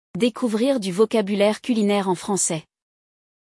✔ Écouter un dialogue authentique entre natifs